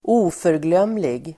Uttal: [²'o:förglöm:lig]